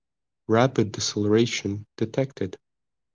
rapid-deceleration-detected.wav